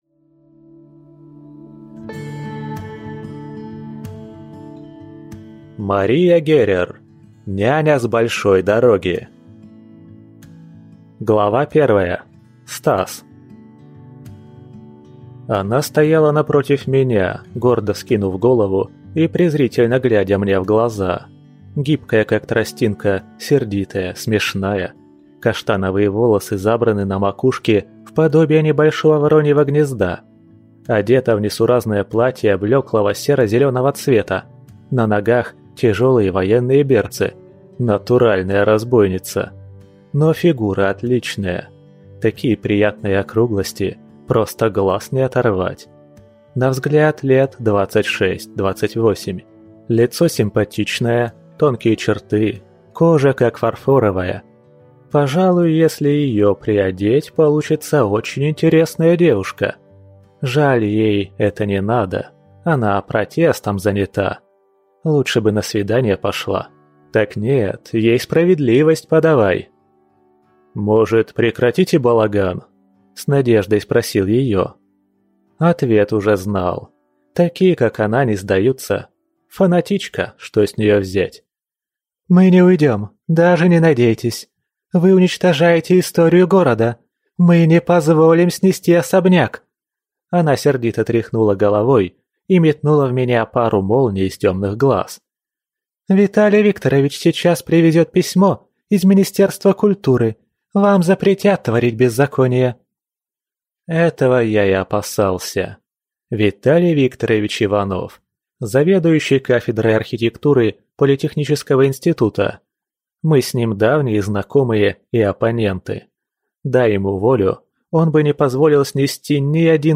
Аудиокнига Няня с большой дороги | Библиотека аудиокниг